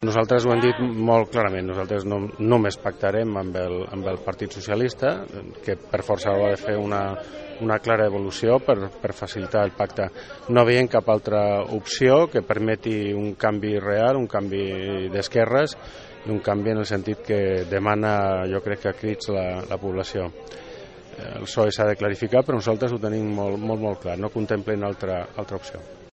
En Comú Podem ha fet aquest diumenge un acte públic al passeig Manuel Puigvert per donar a conèixer algunes de les seves propostes electorals.
A l’acte celebrat aquest diumenge també ha estat present el regidor d’ICV a l’Ajuntament de Calella, Sebastián Tejada.